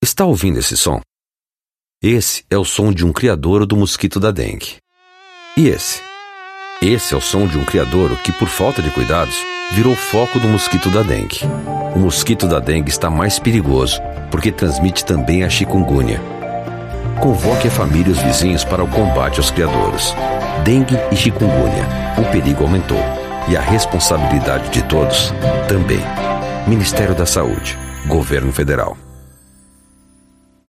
Spot: Campanha de combate à dengue e à febre chikungunya